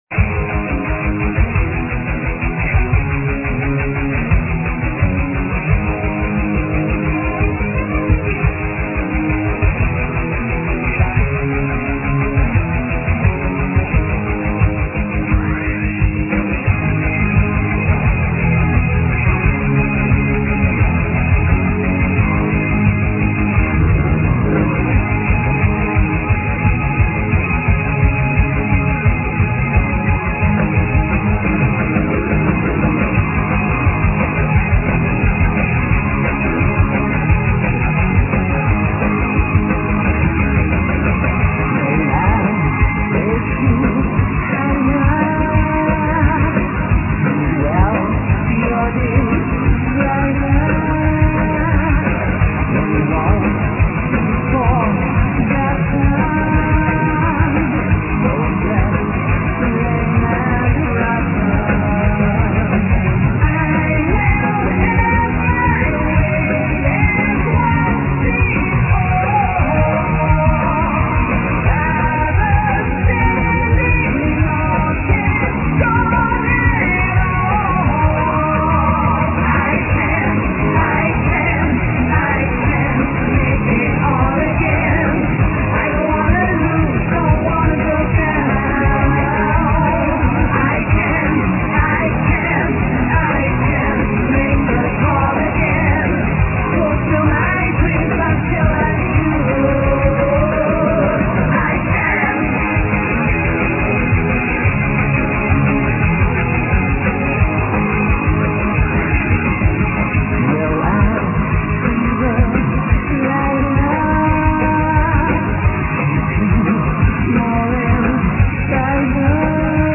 Vocals
Guitars
Bass
Drums
Keyboards